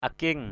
/a-kie̞ŋ/